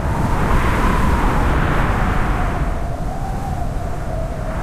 Wind1.ogg